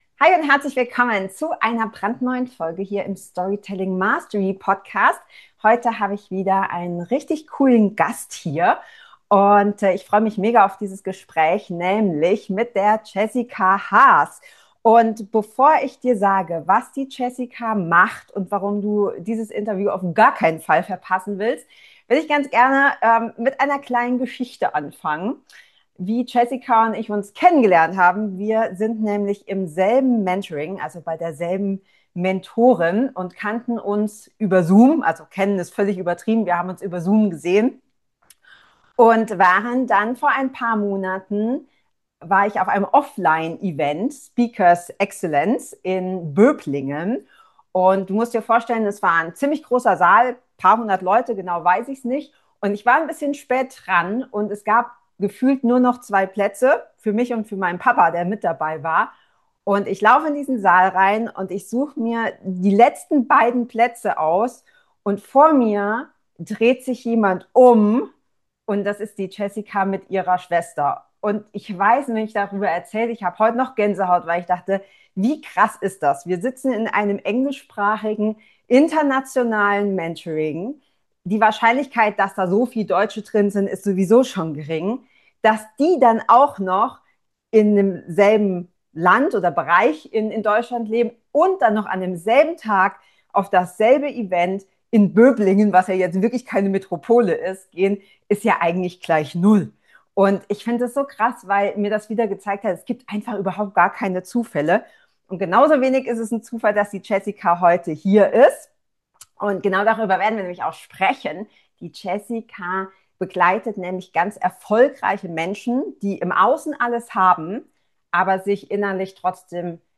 Hier hörst du ein echtes Gespräch über Druck, innere Leere, funktionierende Fassaden und darüber, wie du wieder bei dir ankommst.